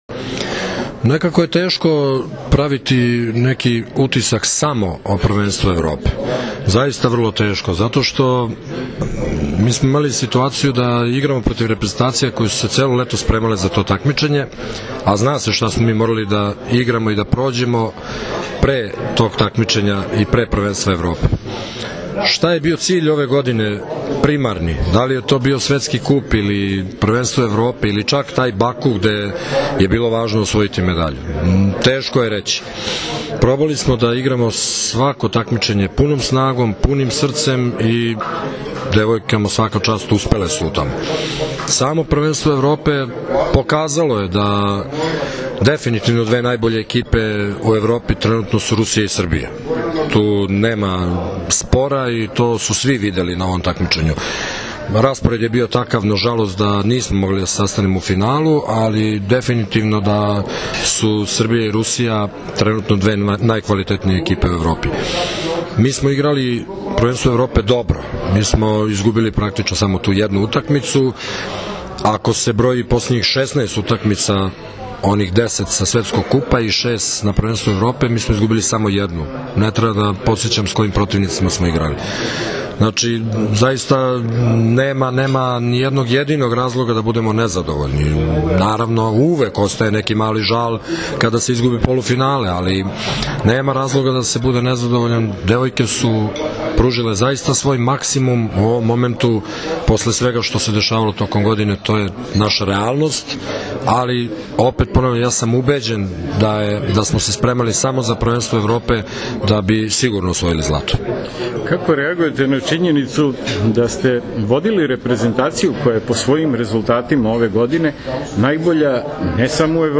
Odbojkašice su zatim produžile u beogradski hotel „M“, gde im je priređen svečani doček.
IZJAVA ZORANA TERZIĆA